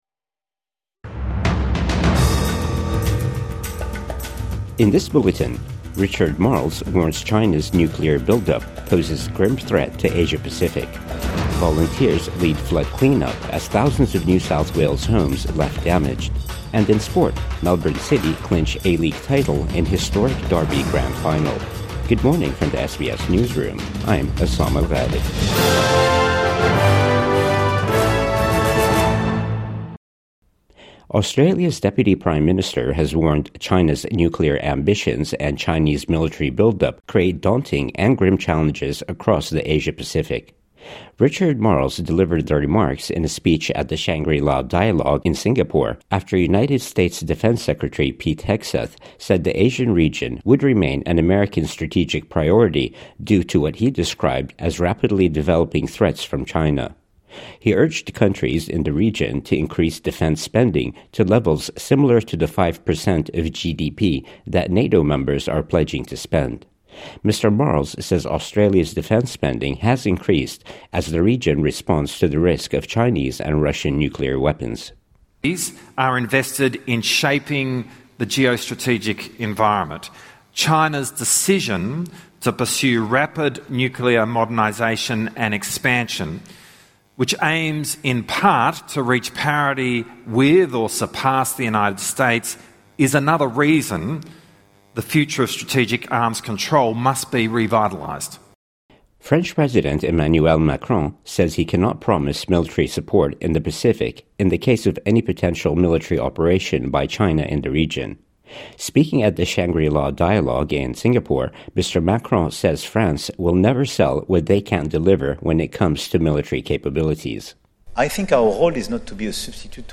Marles warns China’s nuclear build-up poses grim threat to Asia-Pacific | Morning News Bulletin 1 June 2025